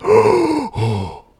breath02.ogg